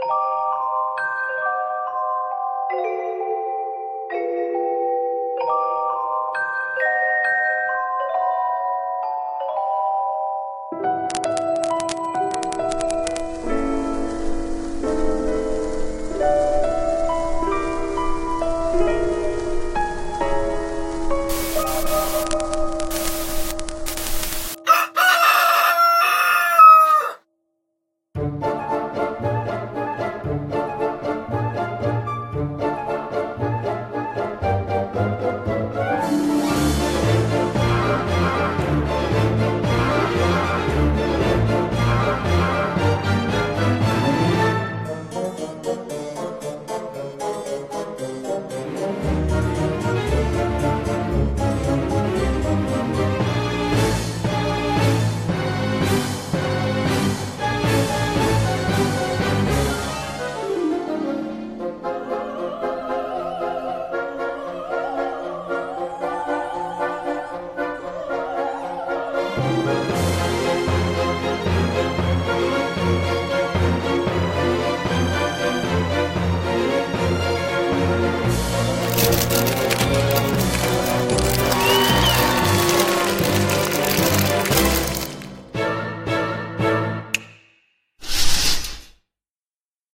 CM風声劇「クライン・レーヴェンの仕立師」 / 仕立て師:【】